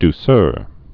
(d-sûr, -sœr)